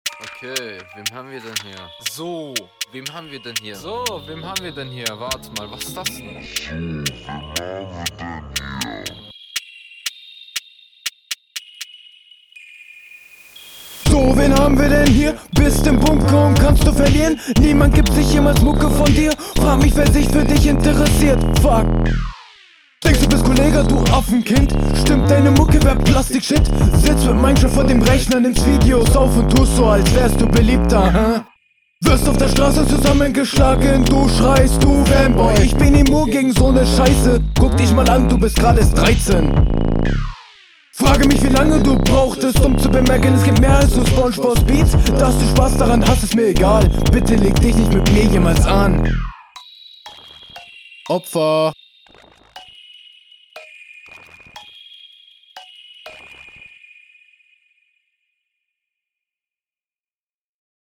Hast dich echt gesteigert vom Flow.